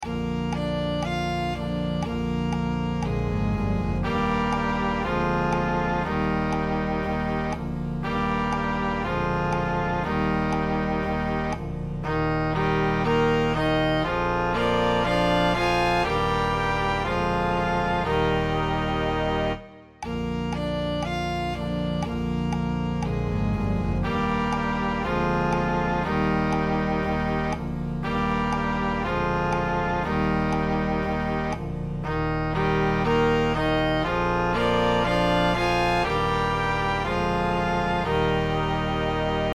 Hot Cross Buns (Horn Eb)